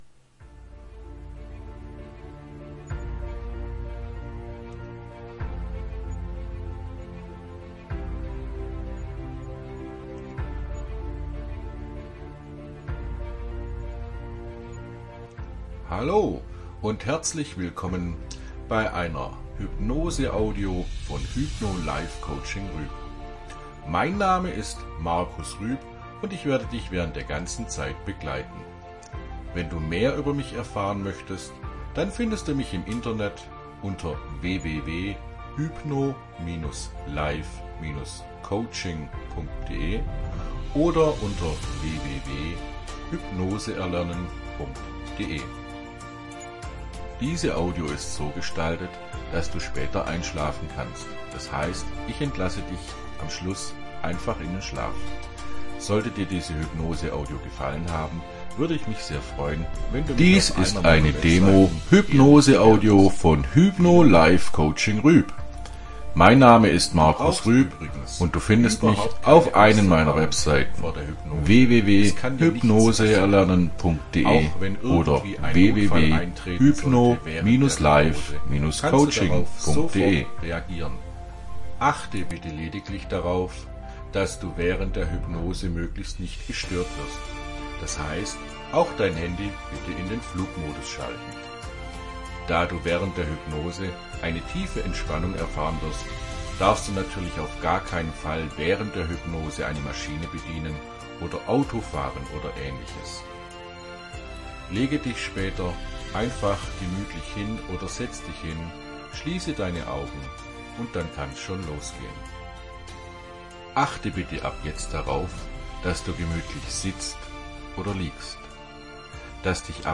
Hypnoseaudios für Fahrschüler